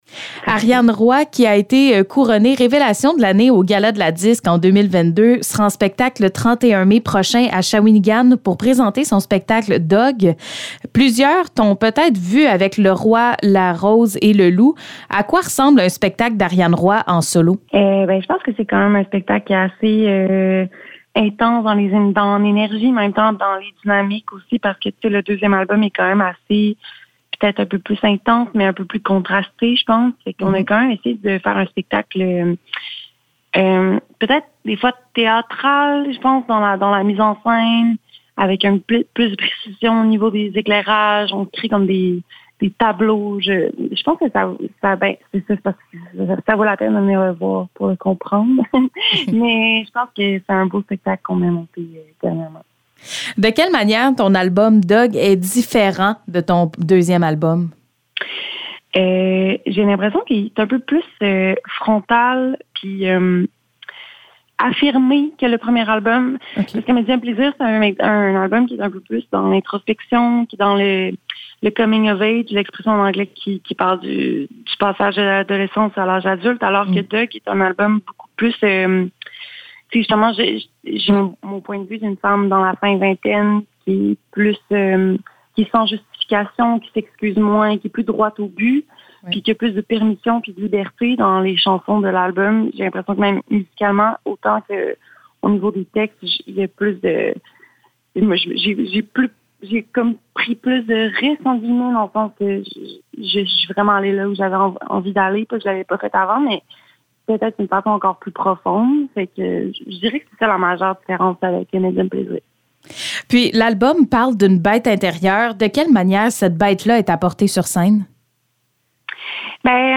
Entrevue avec la chanteuse Ariane Roy